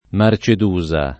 Marcedusa [ mar © ed 2@ a ] top. (Cal.)